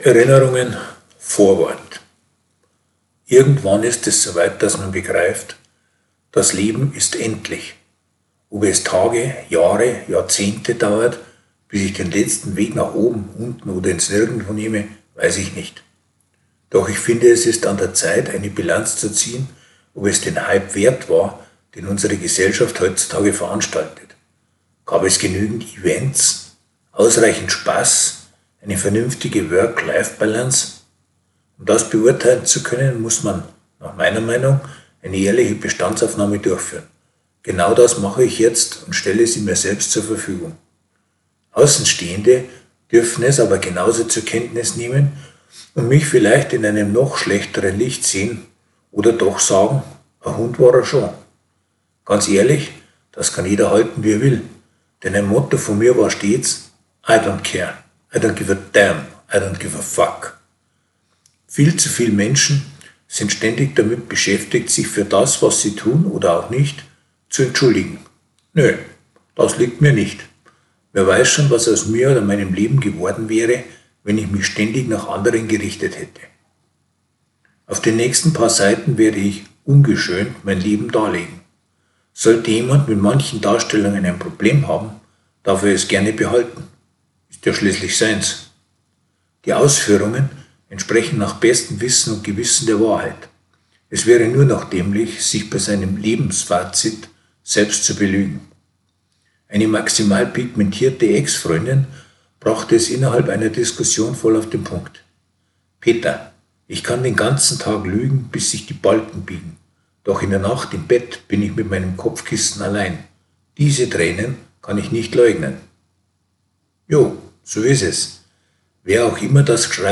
Beitrag vorlesen (2:25 Minuten)